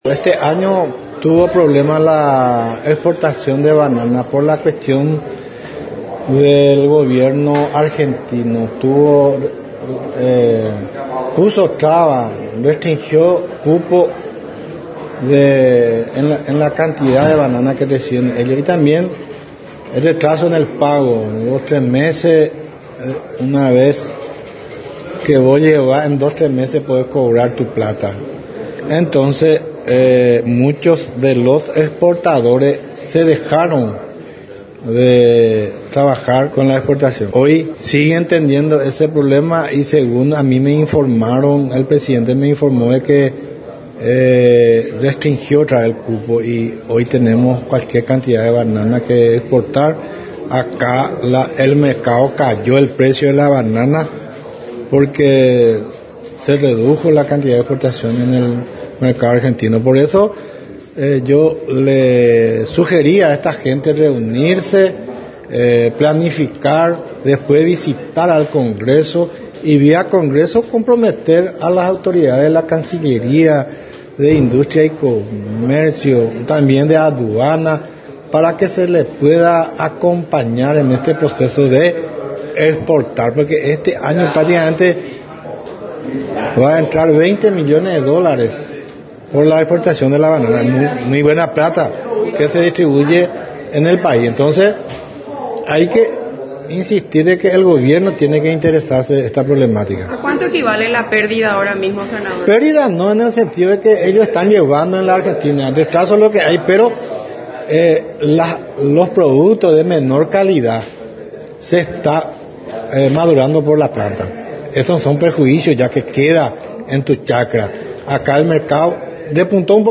El Senador José Ledesma, se refirió a la problemática que atraviesan los productores bananeros y la piña, que están preocupados por la situación económica en Argentina que afecta al sector, ya que el mayor porcentaje de la producción nacional se comercializa al vecino país.